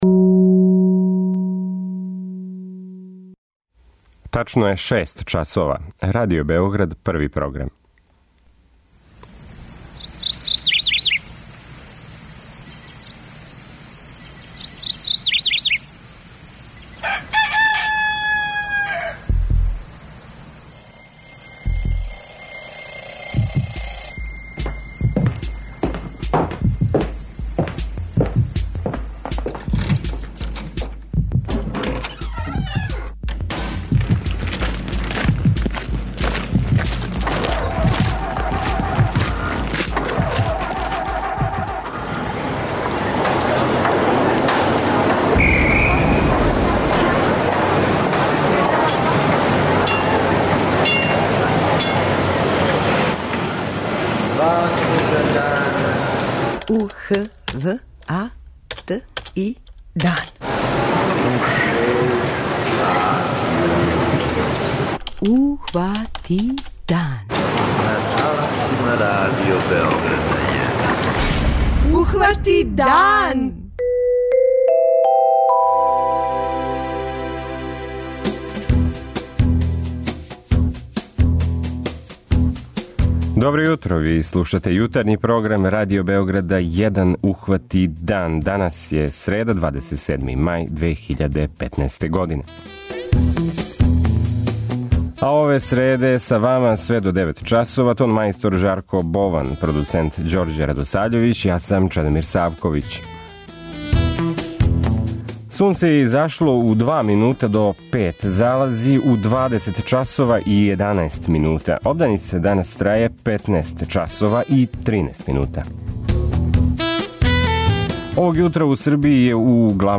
Укључење оперативаца Сектора за ванредне ситуације МУП-а Србије због најављених падавина.